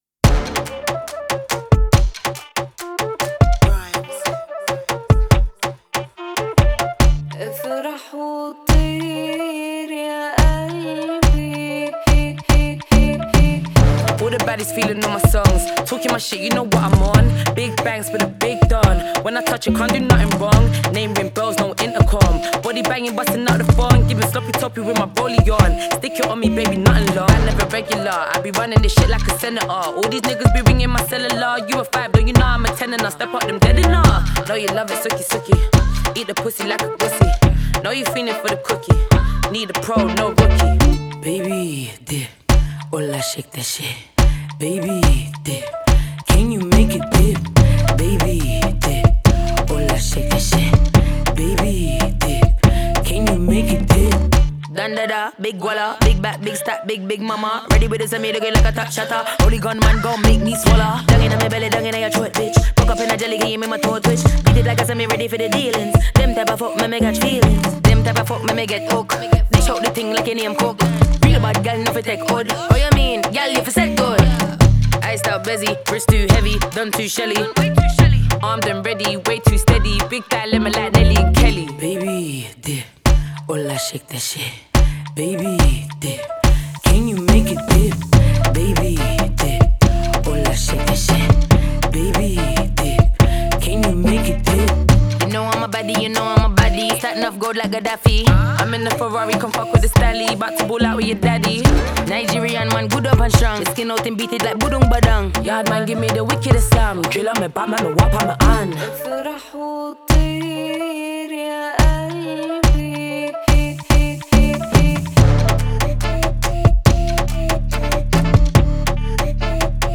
• Жанр: Rap